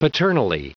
Prononciation du mot paternally en anglais (fichier audio)